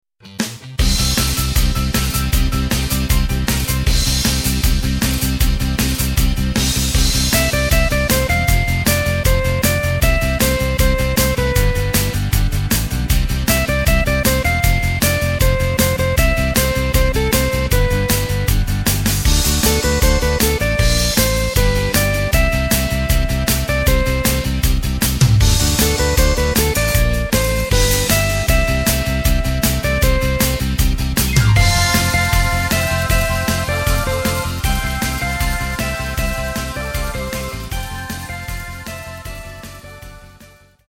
Takt:          4/4
Tempo:         156.00
Tonart:            Dm
Rock aus dem Jahr 1982!